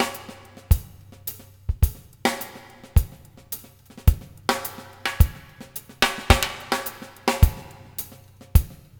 CLB DRUMS -L.wav